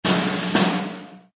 SZ_MM_drumroll.ogg